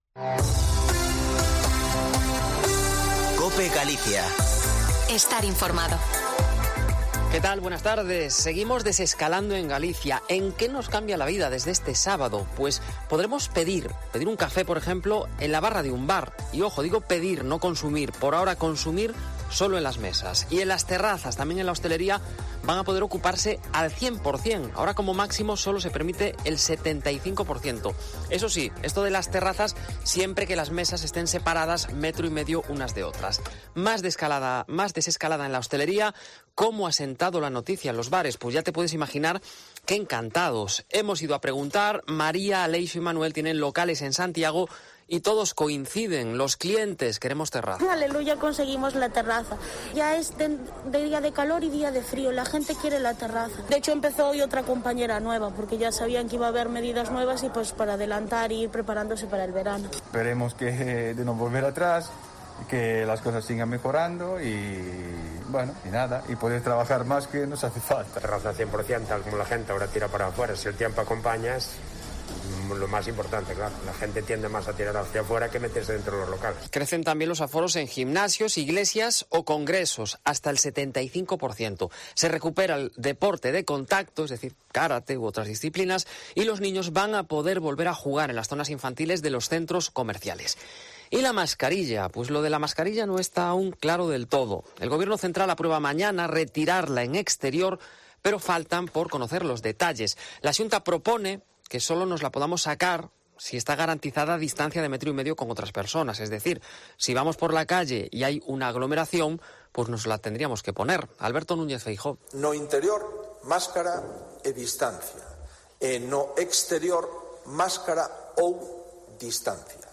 Informativo Mediodia en Cope Galicia 23/06/2021. De 14.48 a 14.58h